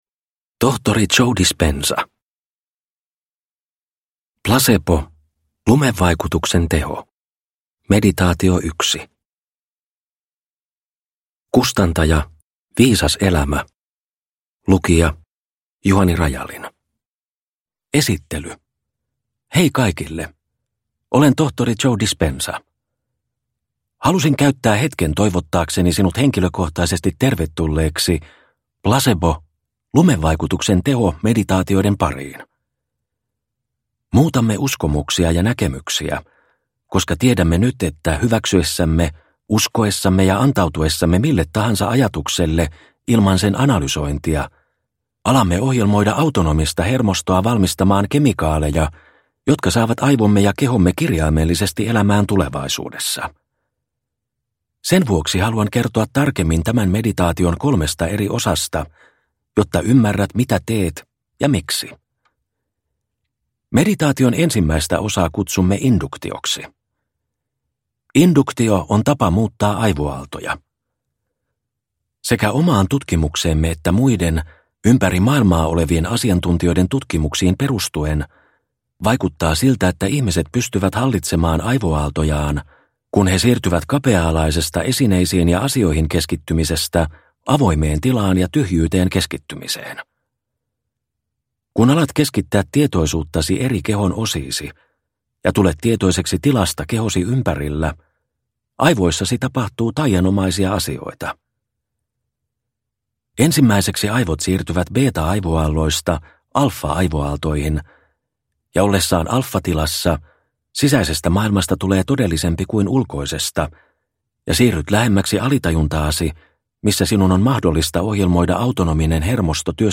Plasebo meditaatio – Ljudbok – Laddas ner